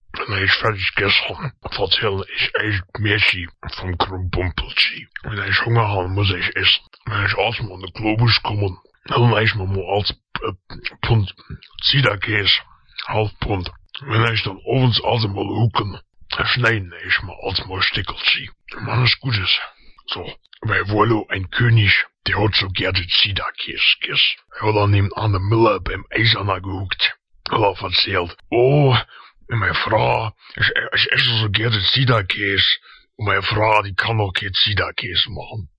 The speaker in the recording doesn’t seem to be speaking very clearly, or this might just be how this language usually sounds.